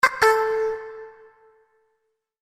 Звуки аськи (ICQ)
• Качество: высокое
Звук сообщения ICQ nУзнаваемый звук ICQ nЗнакомый звук ICQ